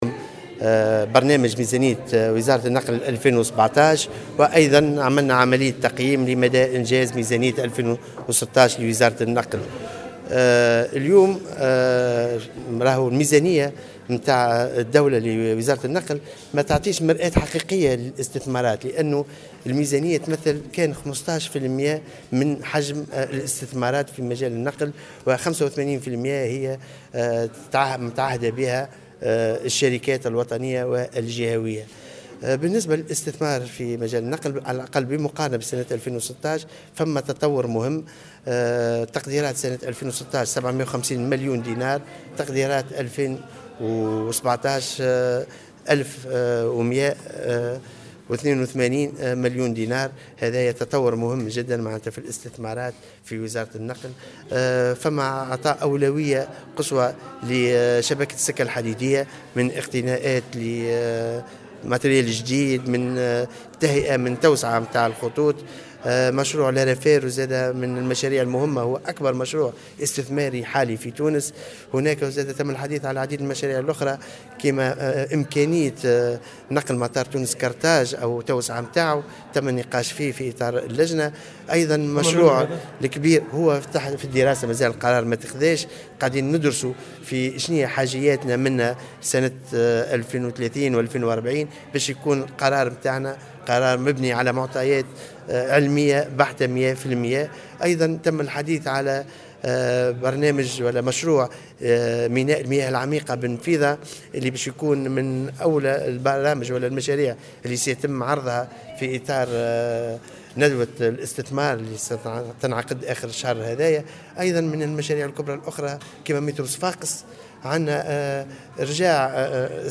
قال وزير النقل أنيس غديرة خلال الاستماع إليه ضمن لجنة الفلاحة والخدمات بالبرلمان اليوم الأربعاء في تصريح لمراسل الجوهرة "اف ام" أن هذا اللقاء مع أعضاء اللجنة والبرلمان كان فرصة لعرض برنامج ميزانية وزارة النقل لسنة 2017 وتقييم لما تم انجازه من برنامج ميزانية النقل لسنة 2016.